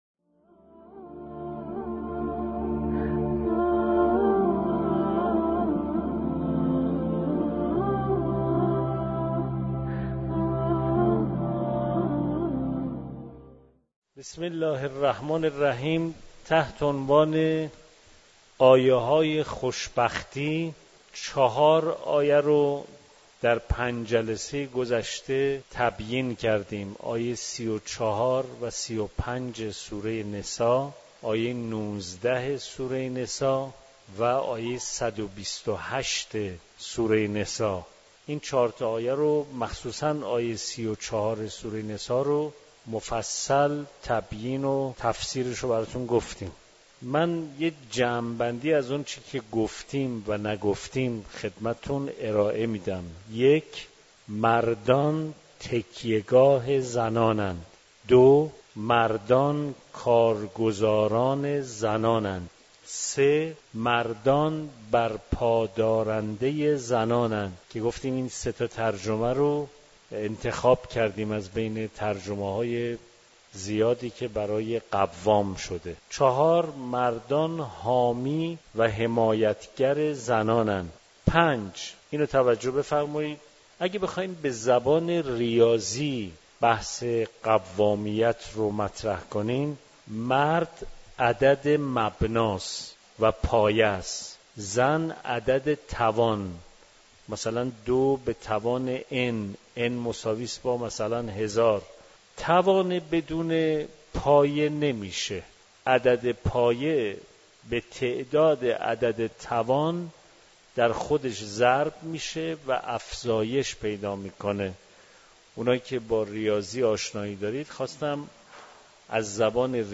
مکان: دارالتفسیر حرم مطهر رضوی